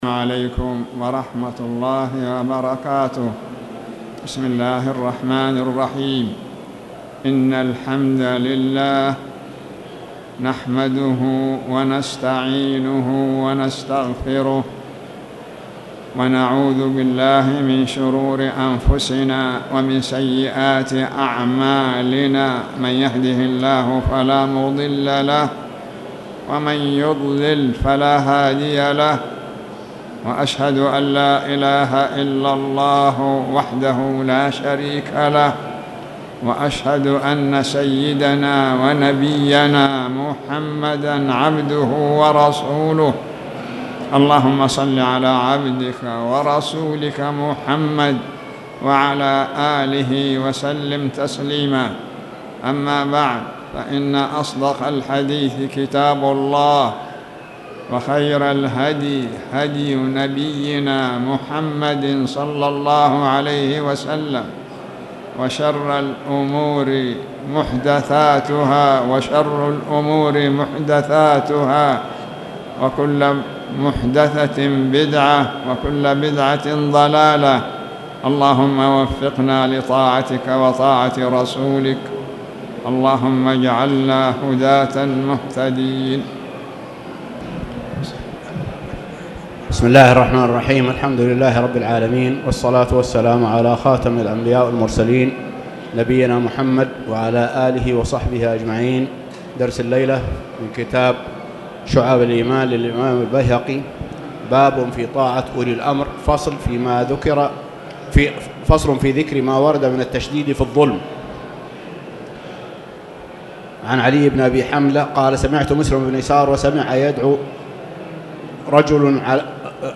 تاريخ النشر ٢٢ صفر ١٤٣٨ هـ المكان: المسجد الحرام الشيخ